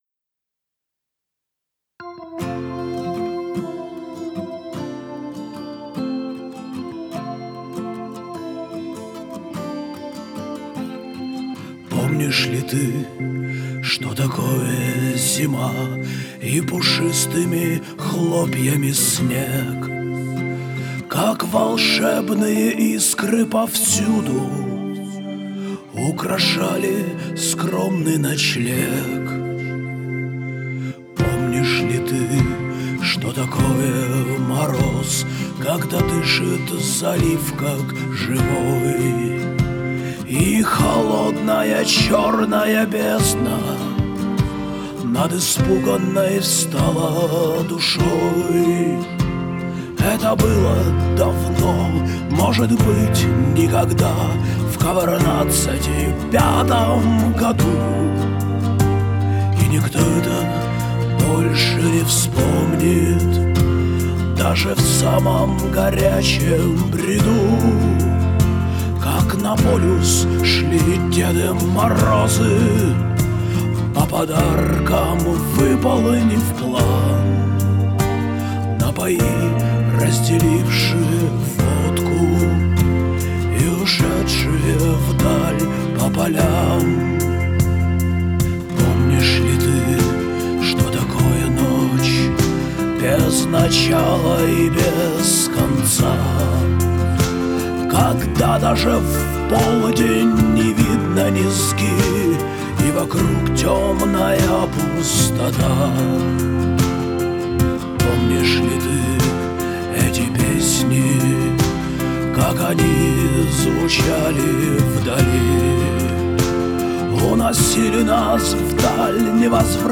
Поп-музыка/Авторская песня. Глобальное потепление.
Это демо, в непривычном для меня стиле.
Я знаю, что вокал здесь выпирает, но в этом демо это целенаправленно, чтобы можно было оценить звук нового микрофона ;)